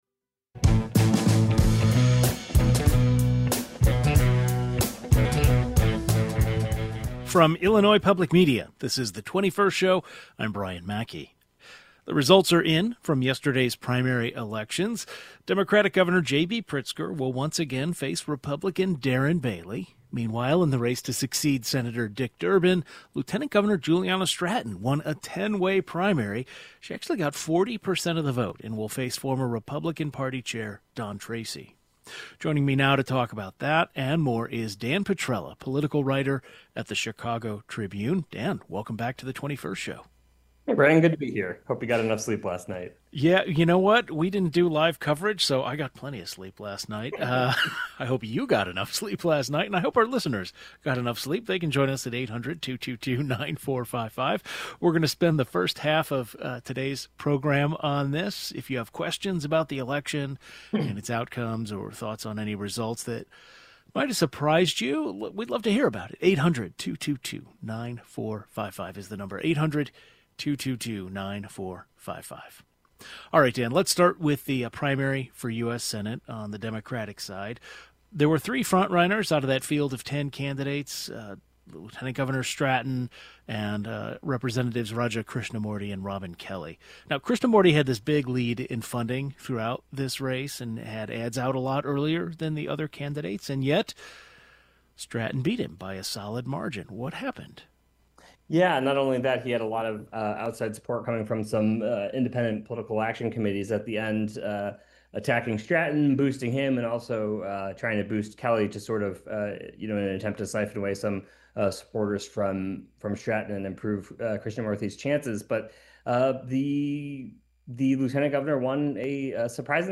A political writer discusses the results of many big races across the state last night and what they mean for the general election in the fall.